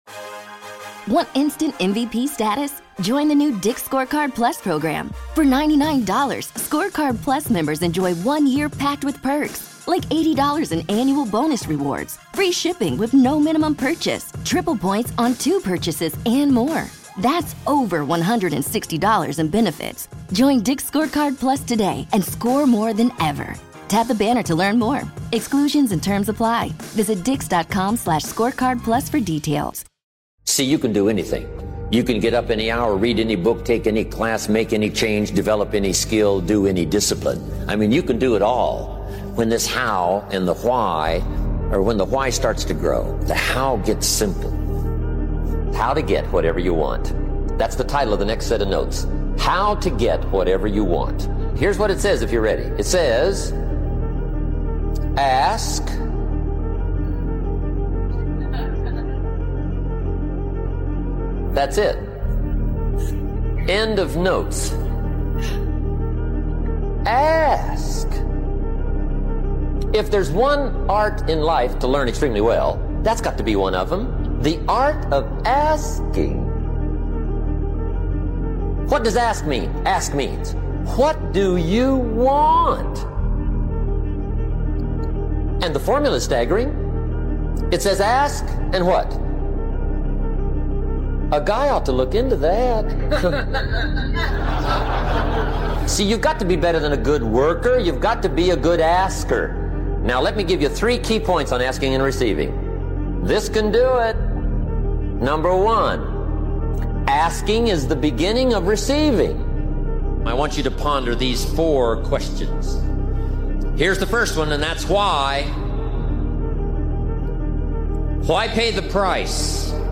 Speaker: Jim Rohn